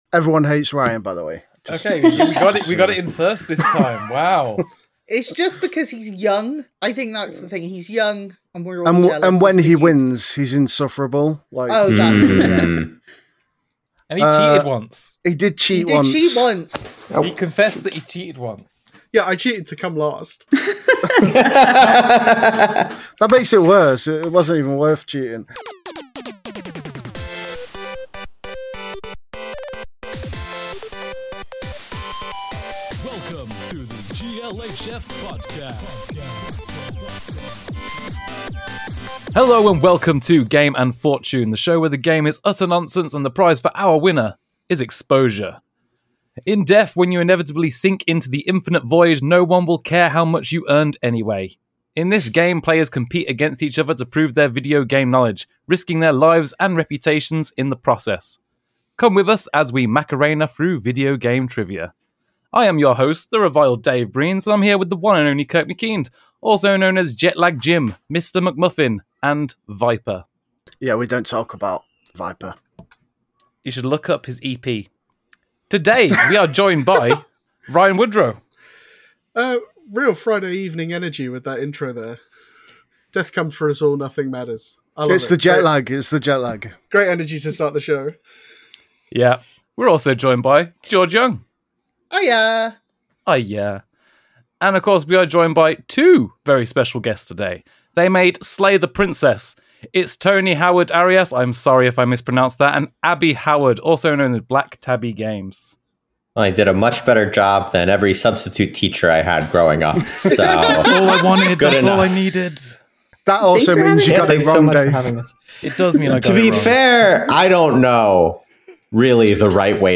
A game show podcast where GLHF regulars and special guests compete in a gaming variety show to earn points and become the overall victor.